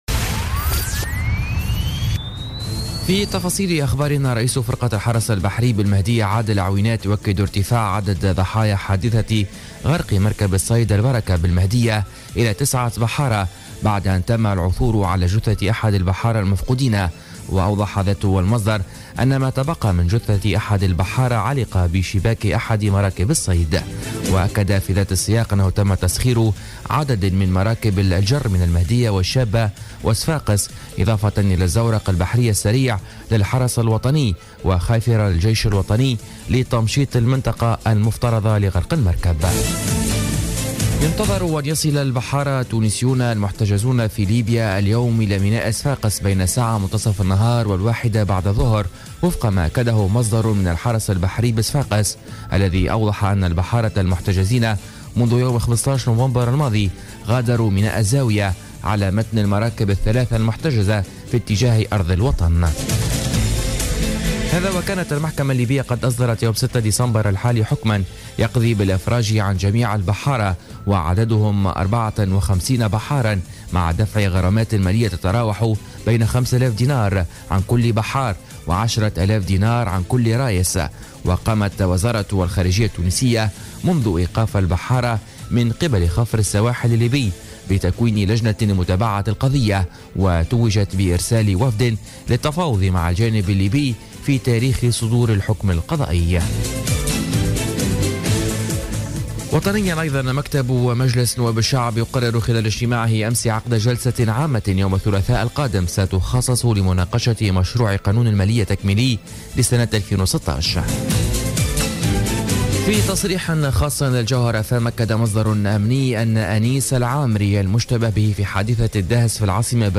نشرة أخبار السابعة صباحا ليوم الخميس 22 ديسمبر 2016